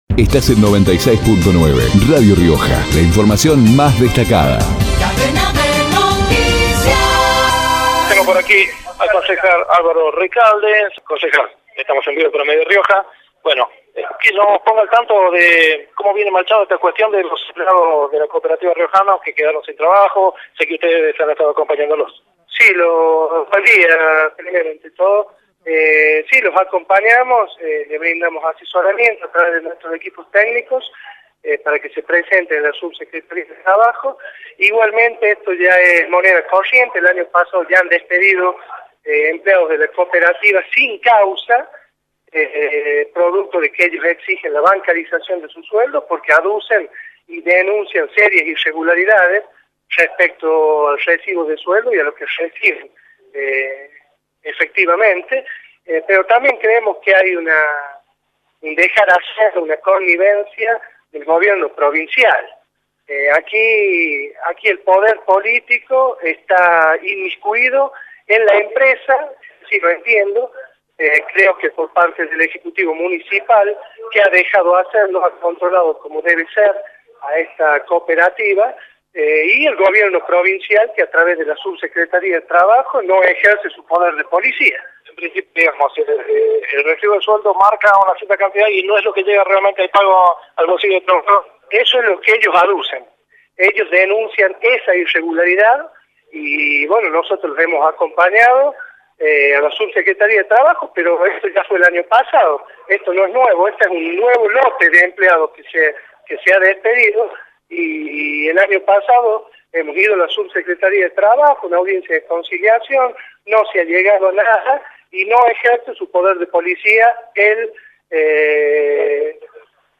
Alvaro Recalde, concejal, por Radio Rioja Ernesto Pérez, concejal, por Radio Rioja Alcira Brizuela, concejal, por Radio Rioja